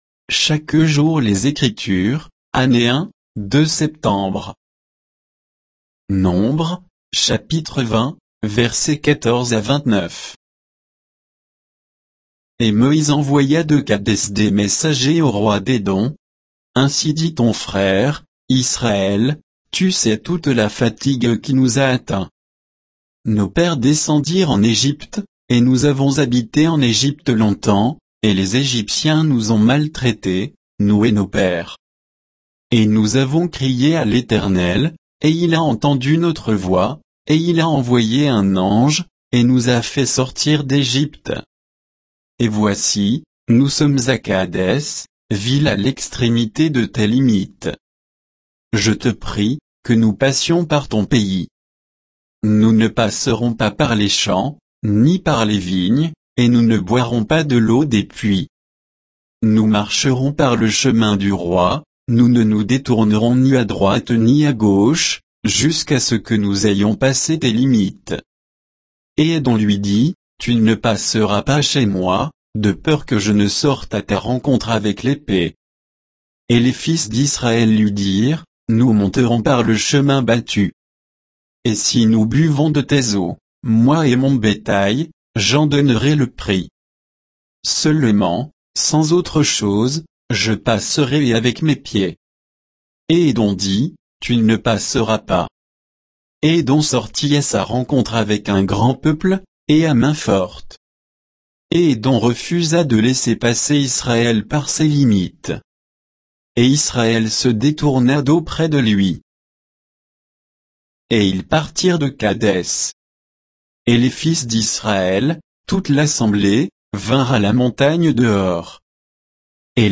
Méditation quoditienne de Chaque jour les Écritures sur Nombres 20, 14 à 29